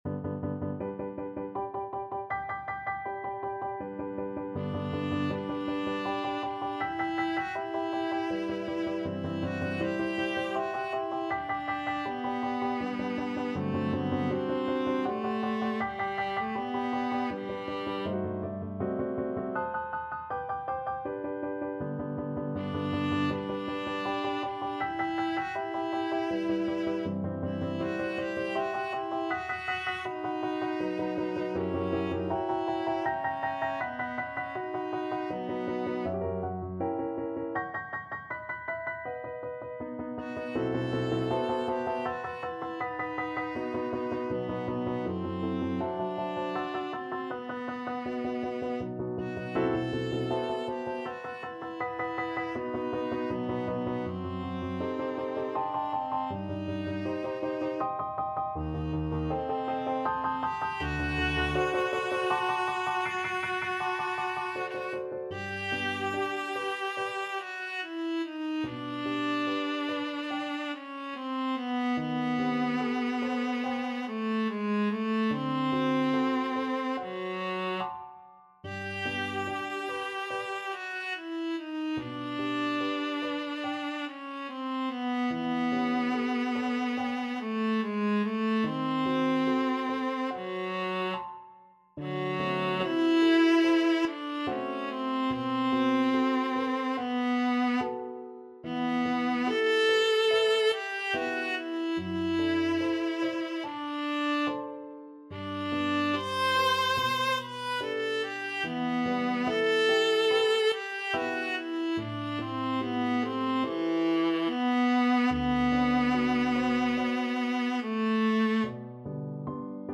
Viola version
Andantino =80 (View more music marked Andantino)
3/4 (View more 3/4 Music)
Classical (View more Classical Viola Music)